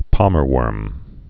(pämər-wûrm)